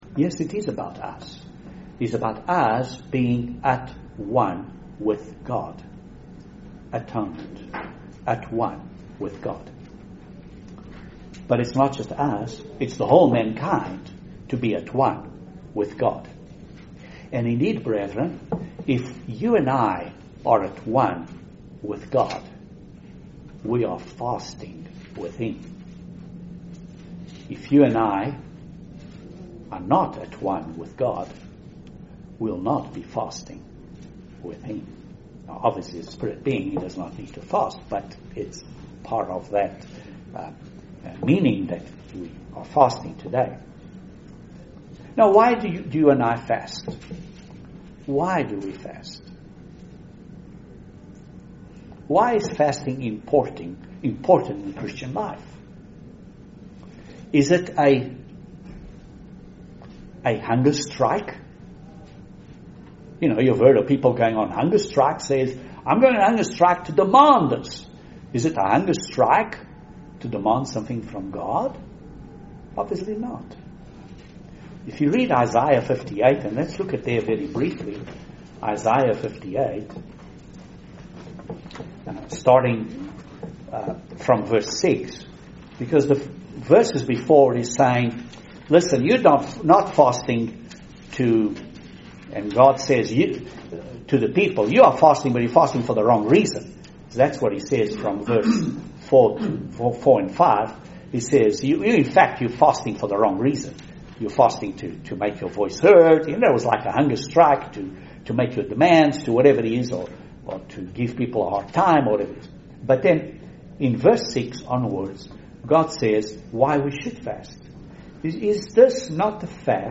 Excellent Sermon on the Day of Atonement. Does Fasting help us to get closer to God?